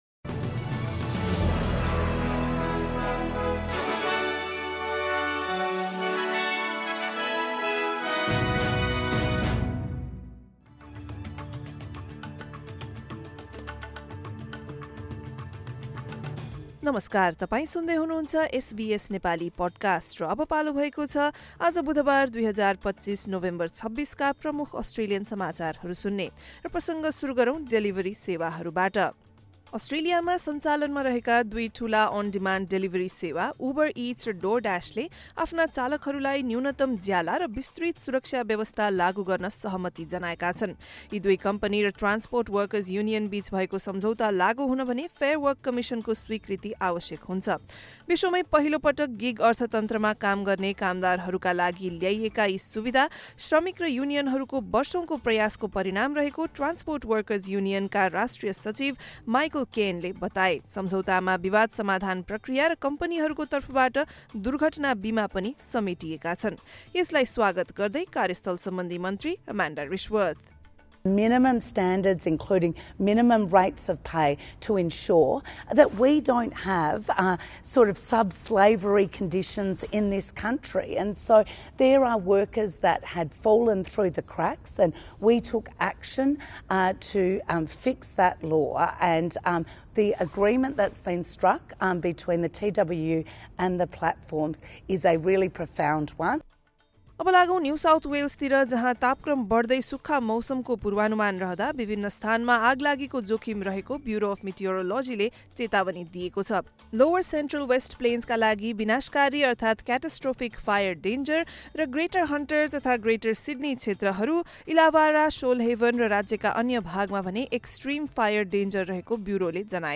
एसबीएस नेपाली प्रमुख अस्ट्रेलियन समाचार: बुधवार, २६ नोभेम्बर २०२५